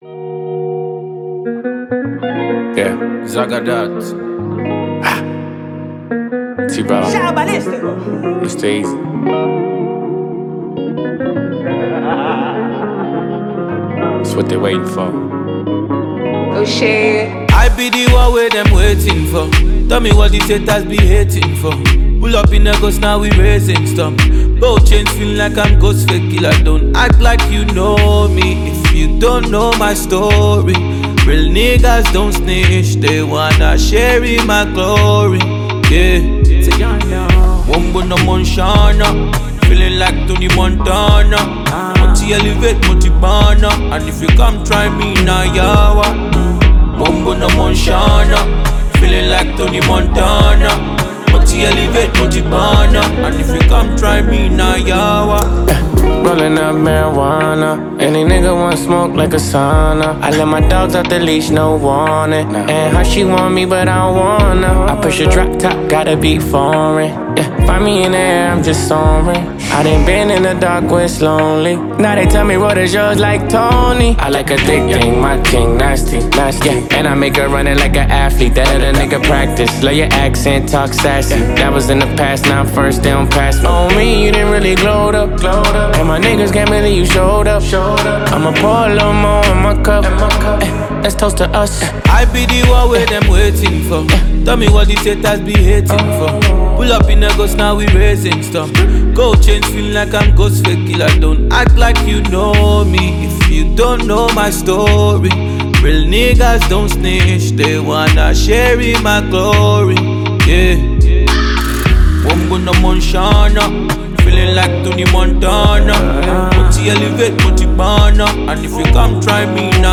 banging new afro-pop tune